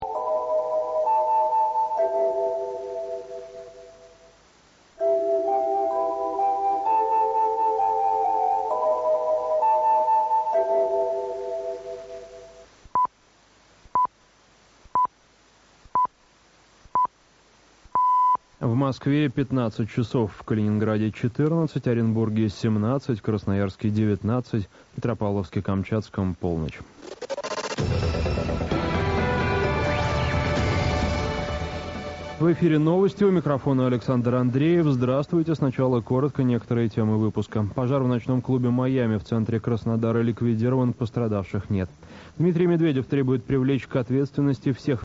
Начало новостей (Маяк, 08.12.2009)